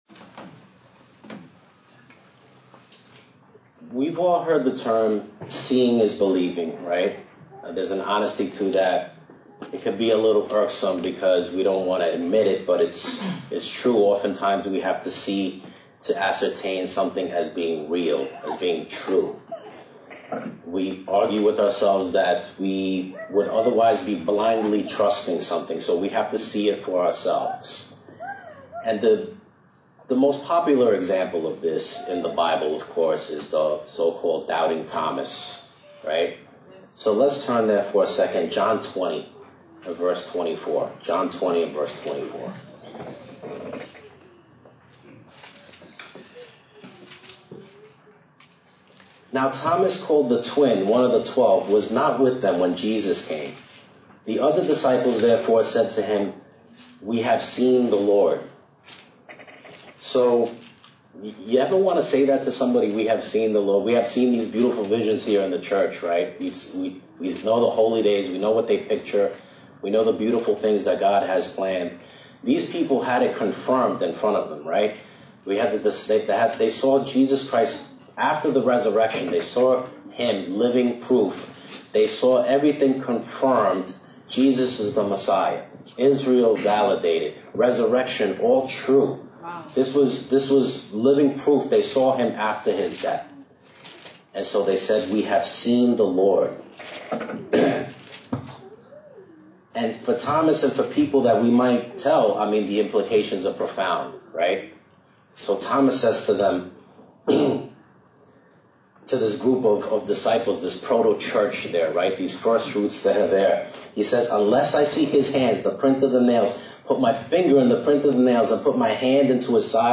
Sermons
Given in New York City, NY